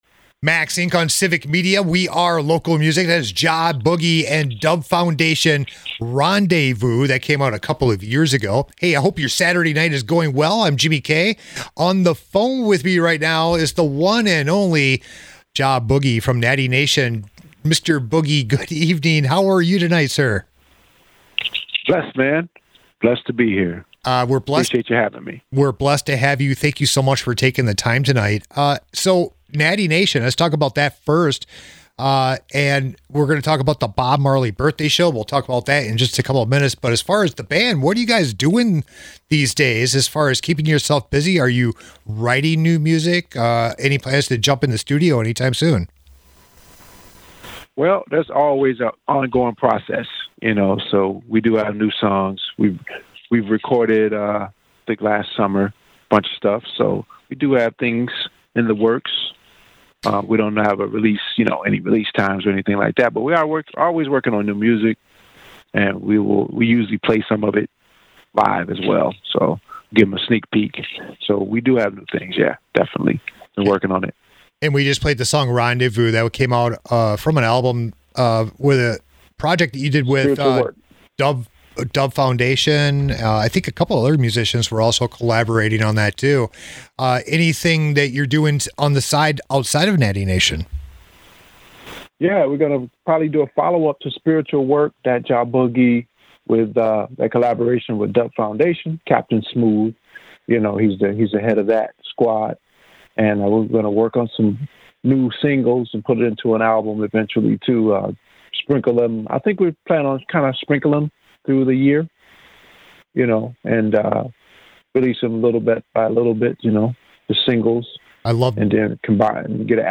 back on Max Ink Radio